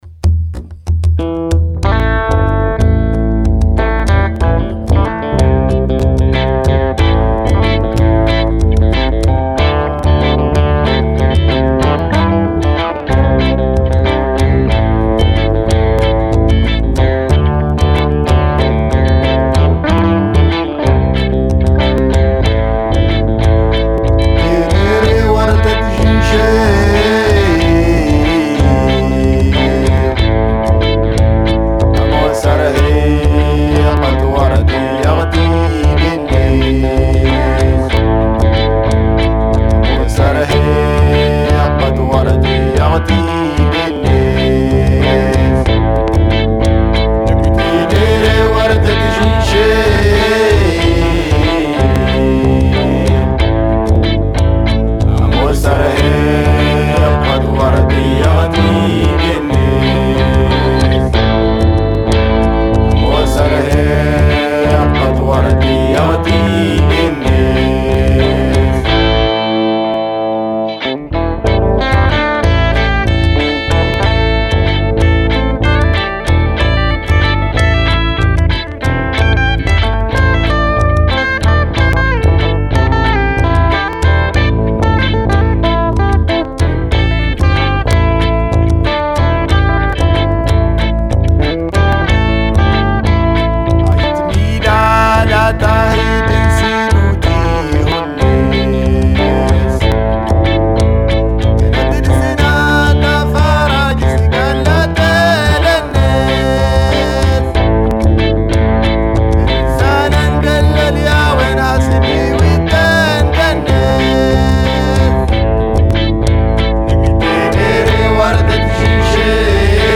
Post punk eta punk doinu ilunak heldu dira gure saiora